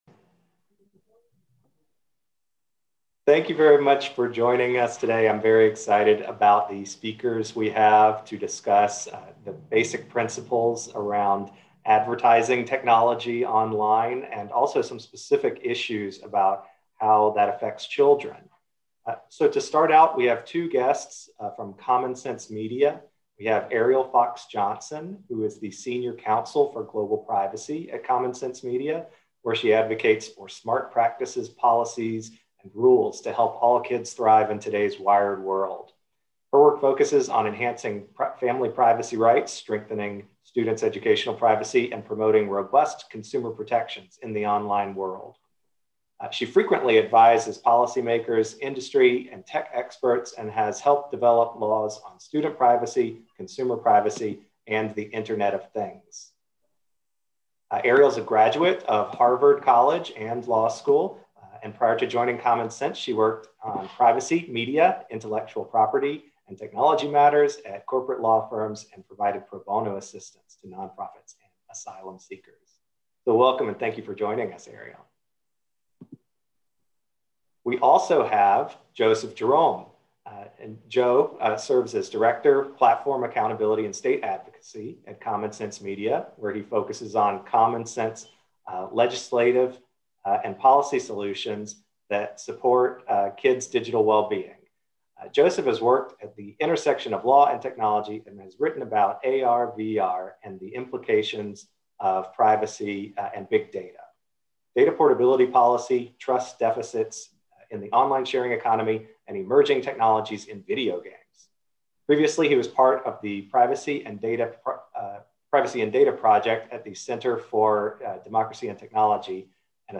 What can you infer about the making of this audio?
UPDATE: An event recording is now available, along with presentation slides.